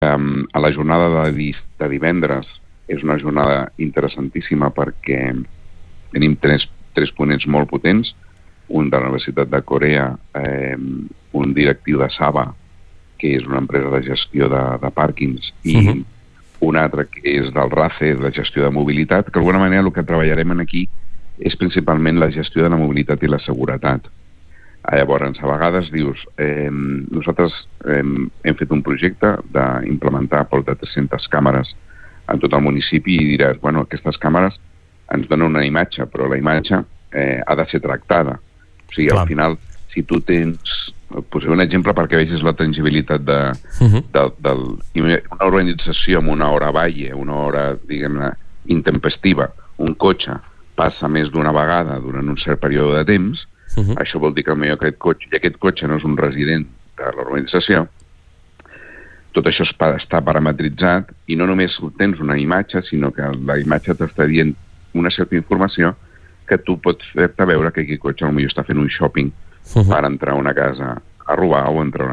El regidor de promoció econòmica de Calonge i Sant Antoni, Arturo Pradas, ens va visitar al Supermatí de dimecres passat per detallar-nos les jornades Sustainable Mobility for a Safe & Smart Town que se celebraran aquest cap de setmana.
entrevistaprocessada_hNDQ84s8.mp3